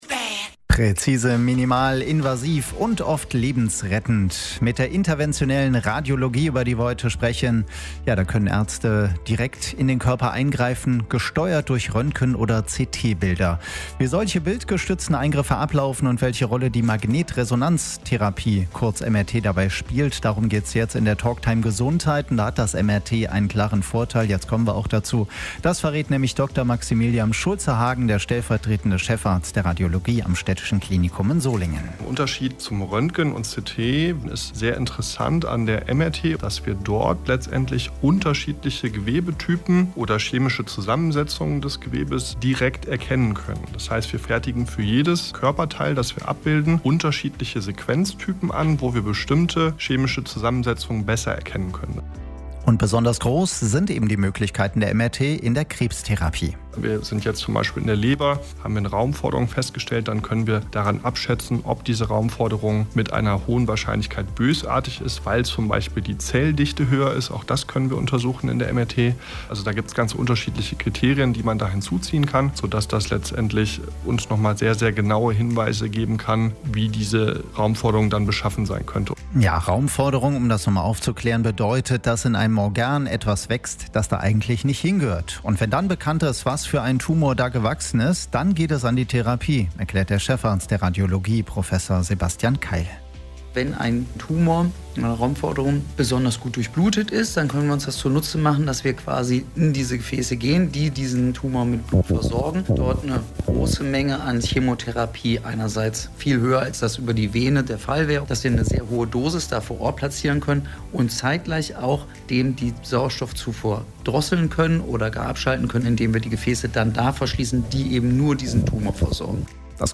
Die Radiosprechstunde lief am Samstag, 25. Juli 2025, von 12 bis 13 Uhr bei Radio RSG und kann hier nachgehört werden.